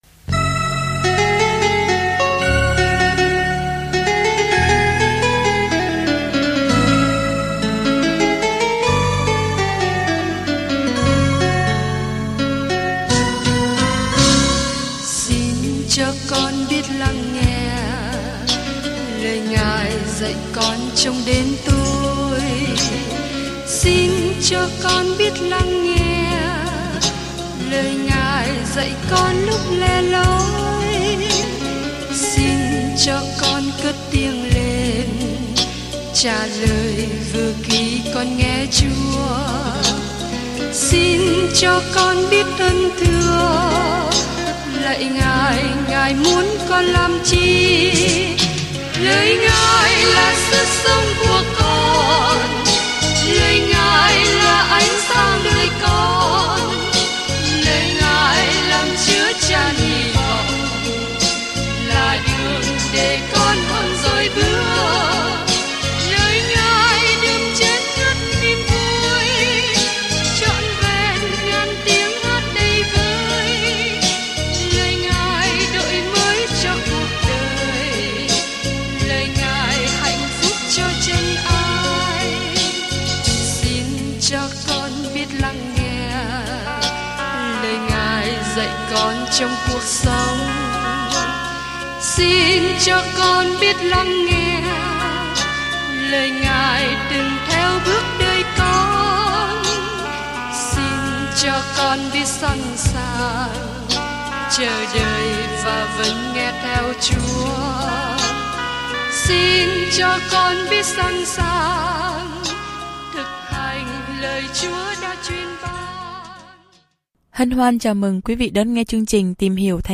Du lịch hàng ngày qua Giô-suê khi bạn nghe nghiên cứu âm thanh và đọc những câu chọn lọc từ lời Chúa.